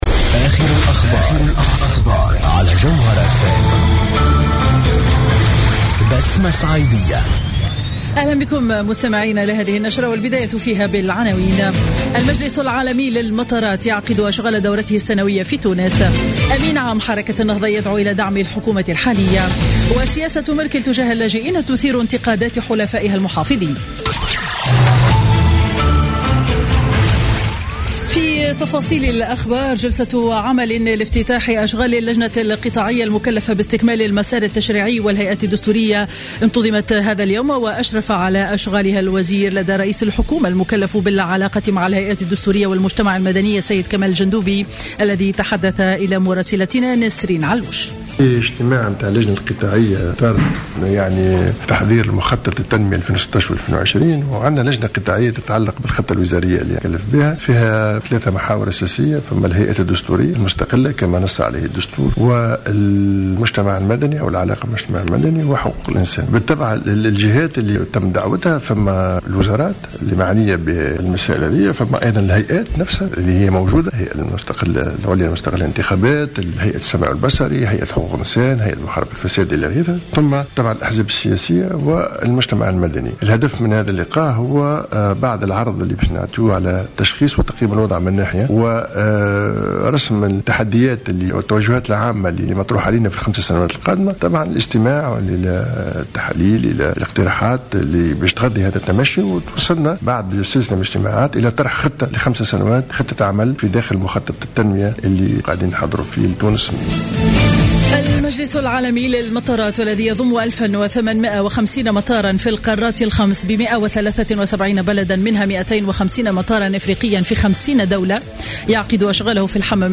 نشرة أخبار منتصف النهار ليوم الإثنين 19 أكتوبر 2015